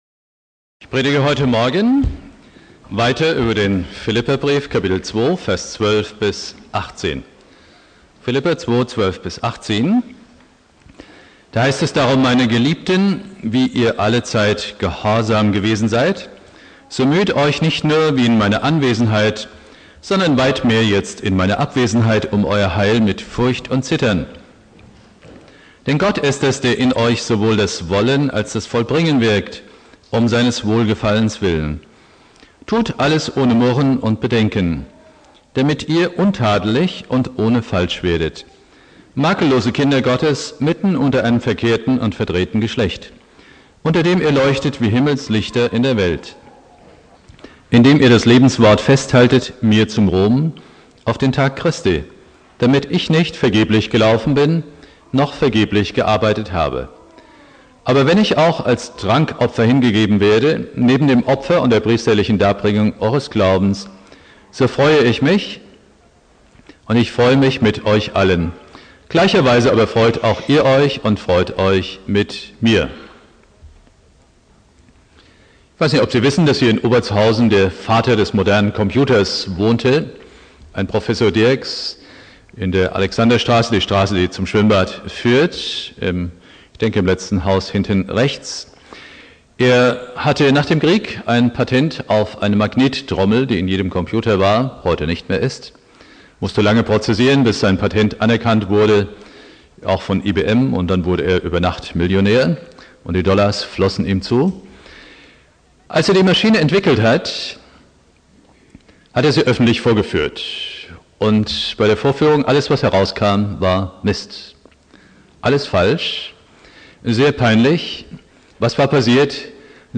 Predigt
(schlechte Aufnahmequalität) Bibeltext: Philipper 2,12-18 Dauer